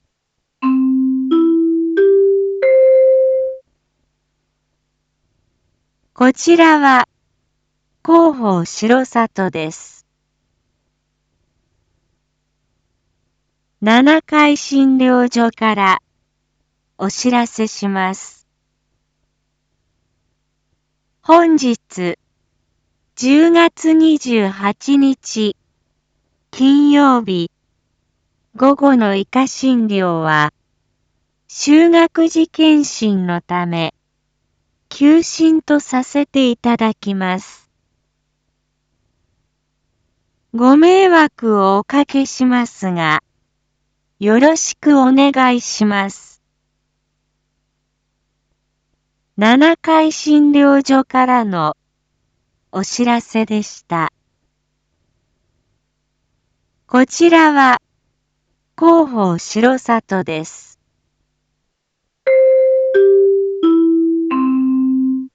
Back Home 一般放送情報 音声放送 再生 一般放送情報 登録日時：2022-10-28 07:01:06 タイトル：R4.10.28 7時放送分 インフォメーション：こちらは広報しろさとです。